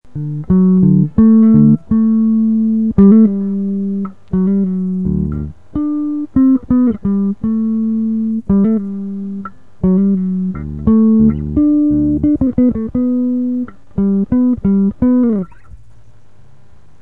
bass.mp3